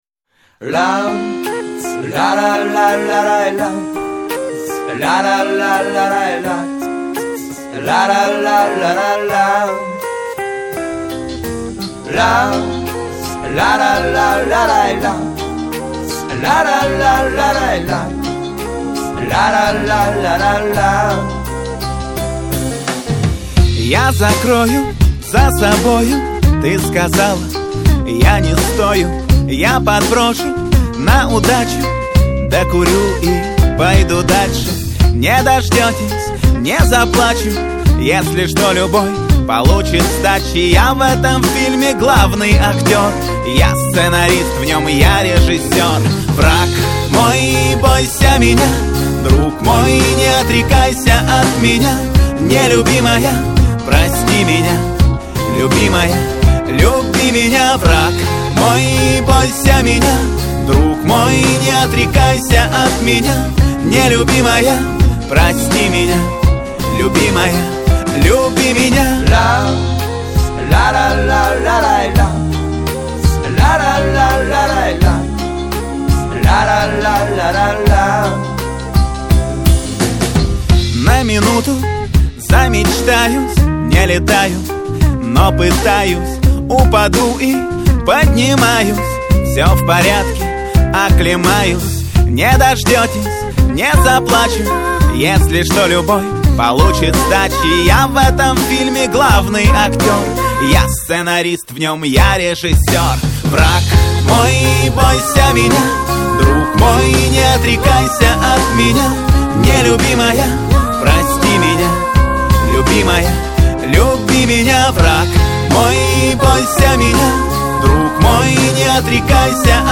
Категория: Альтернатива